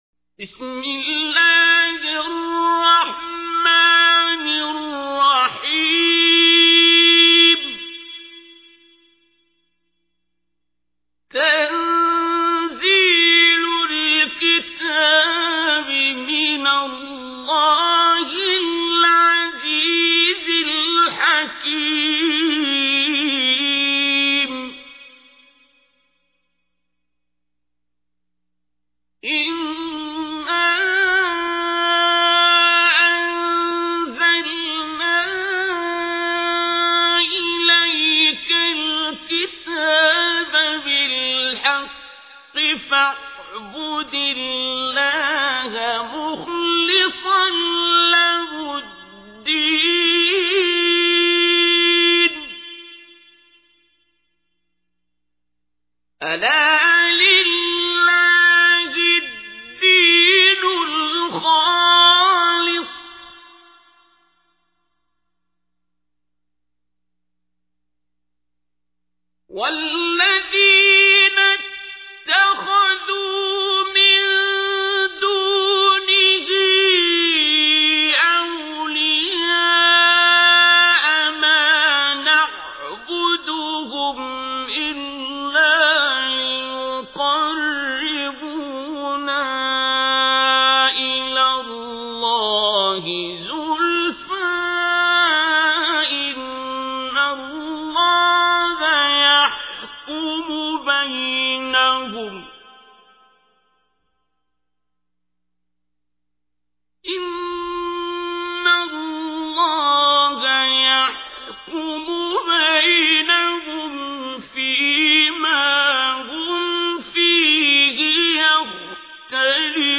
تحميل : 39. سورة الزمر / القارئ عبد الباسط عبد الصمد / القرآن الكريم / موقع يا حسين